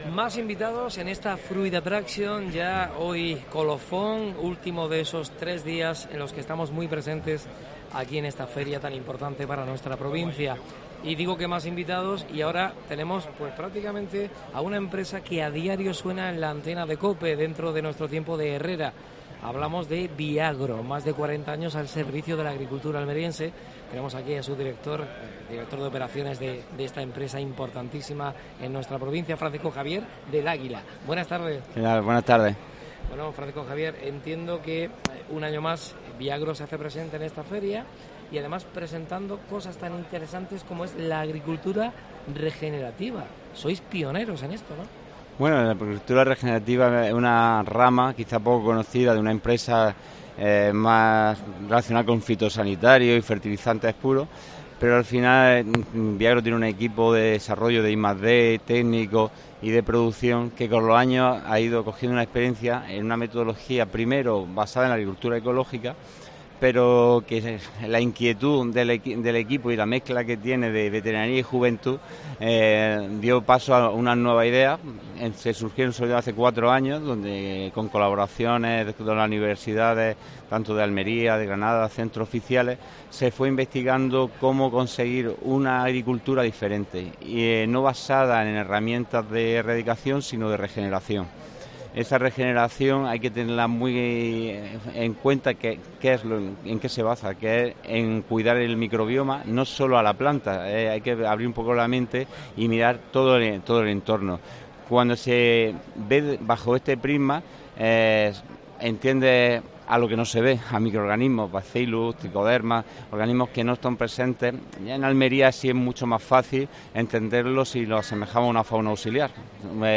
AUDIO: Especial Fruit Attraction desde Madrid.